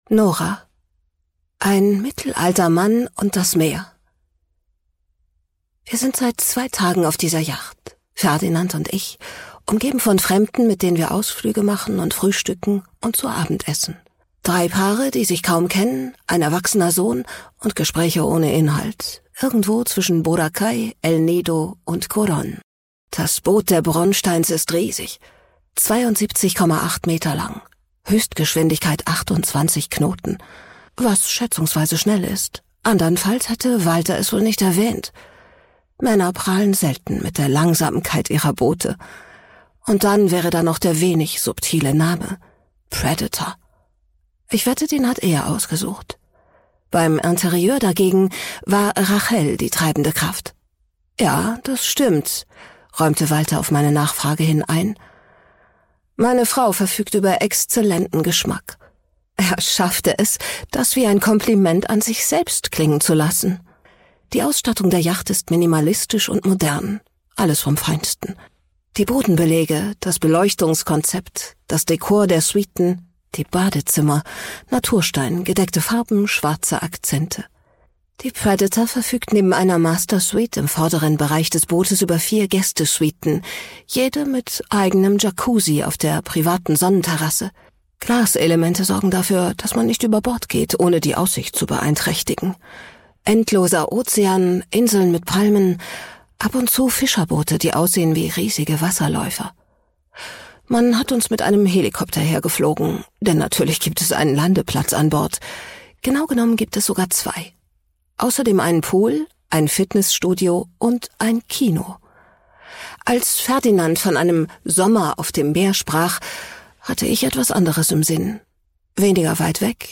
Produktionsart: ungekürzt